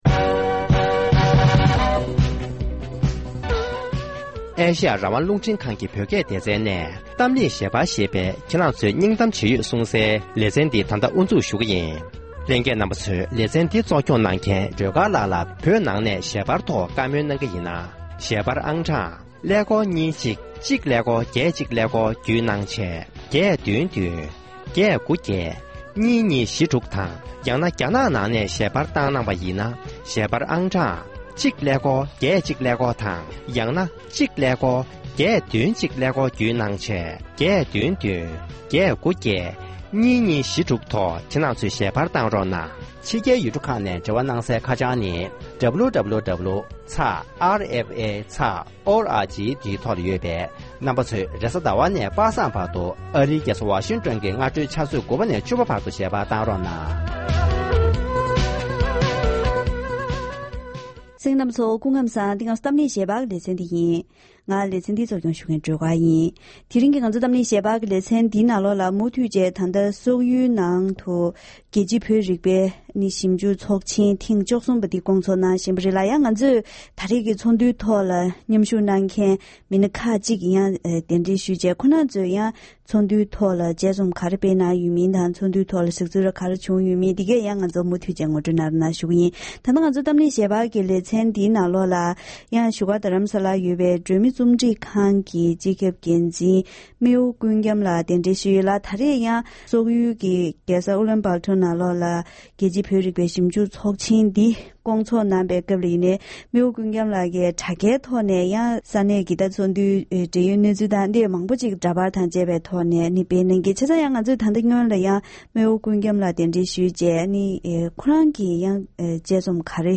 ༄༅༎དེ་རིང་གི་གཏམ་གླེང་ཞལ་པར་ལེ་ཚན་ནང་སོག་ཡུལ་དུ་ཚོགས་བཞིན་པའི་རྒྱལ་སྤྱིའི་བོད་རིག་པའི་ཚོགས་ཆེན་ཐོག་བོད་ཀྱི་གསར་འགྱུར་ཉམས་ཞིབ་དང་ཀིརྟི་བླ་བྲང་གི་འདས་པའི་ལོ་རྒྱུས། དེ་བཞིན་ཕྱི་རྒྱལ་ཡུལ་གྲུའི་ནང་བོད་སྐད་ཡིག་སློབ་ཁྲིད་བྱེད་ཕྱོགས་སོགས་ཀྱི་སྐོར་ལ་དཔྱད་རྩོམ་ངོ་སྤྲོད་གནང་བཞིན་ཡོད་པའི་ཐད་འབྲེལ་ཡོད་དང་བཀའ་མོལ་ཞུས་པ་ཞིག་གསན་རོགས་གནང་།།